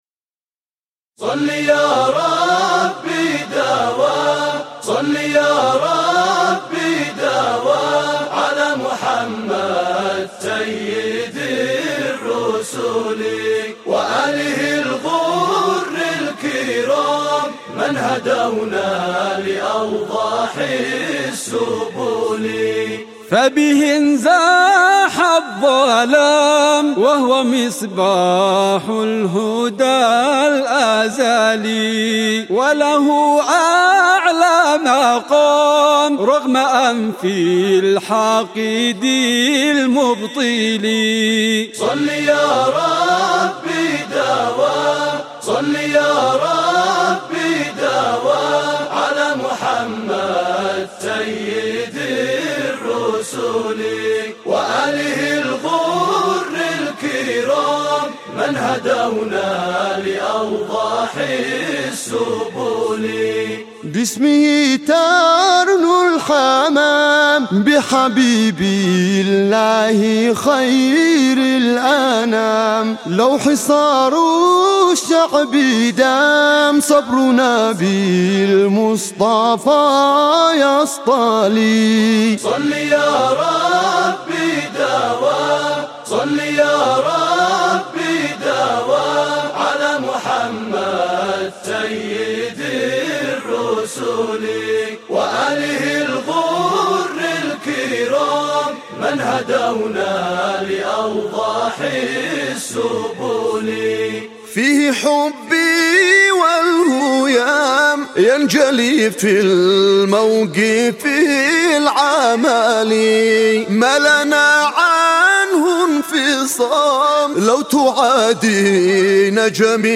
mp3 بدون موسيقى